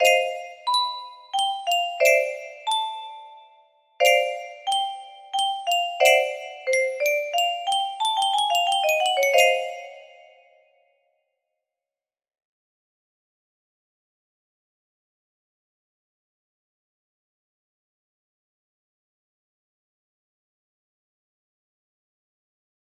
Musicano LD 1 Pre music box melody